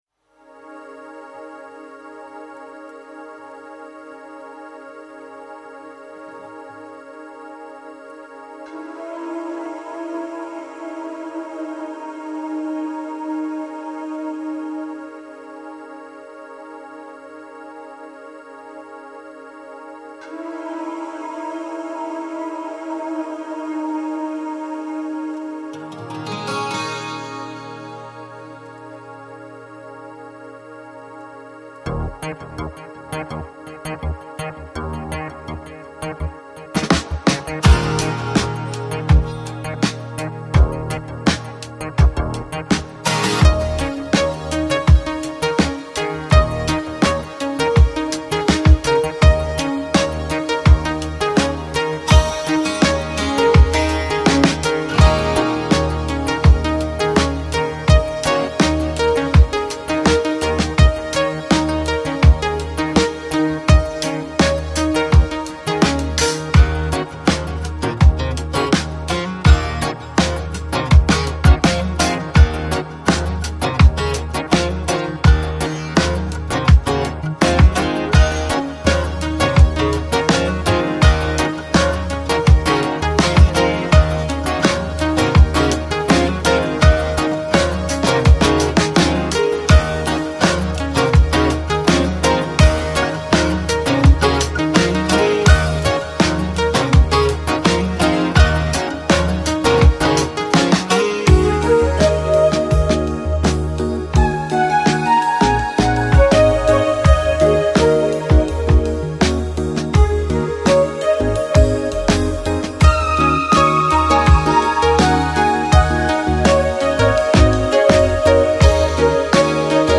Жанр: Balearic | Folk psychedelic